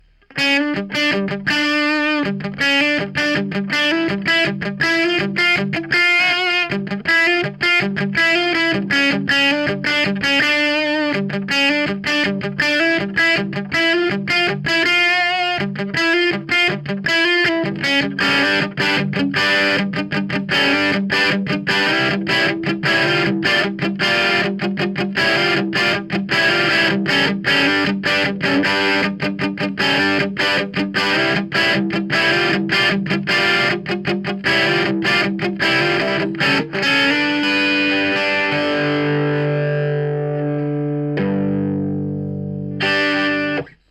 HiStrings.mp3